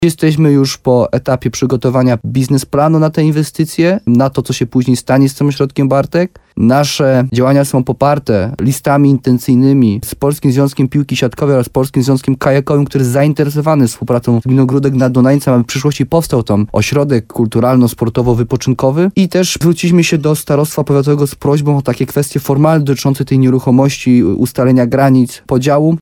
Plany są ambitne i jak ujawnił w programie Słowo za Słowo w radiu RDN Nowy Sącz wójt Jarosław Baziak, rozmowy na ten temat trwały już od roku.